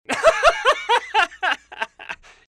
Risa